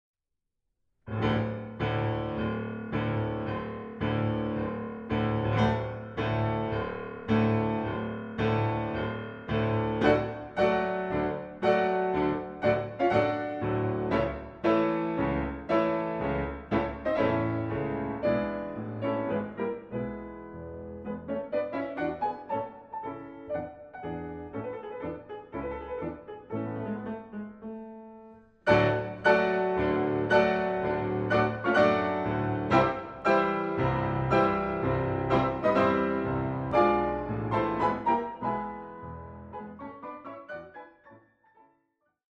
Walzer, Polkas und Märsche
Transkriptionen für Klavier zu vier Händen